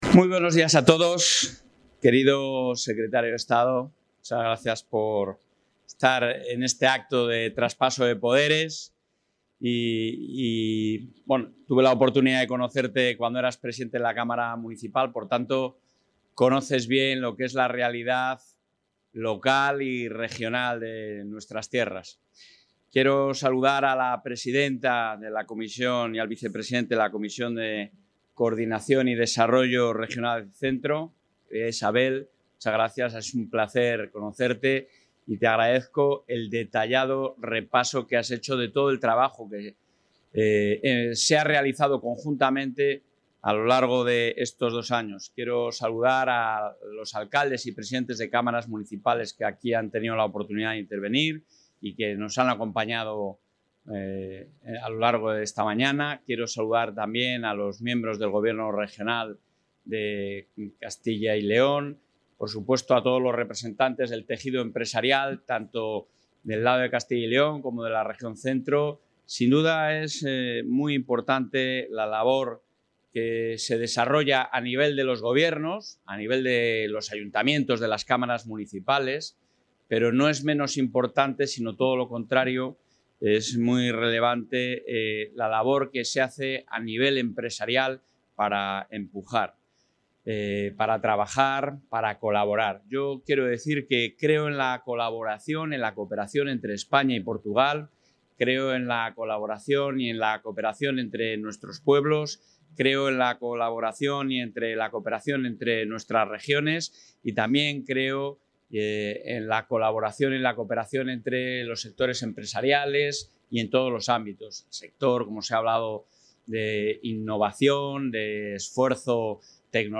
En el VI Consejo Plenario de la Comunidad de Trabajo Centro de Portugal-Castilla y León (CENCYL) que se ha celebrado hoy en Aveiro, el...
Intervención del presidente de la Junta.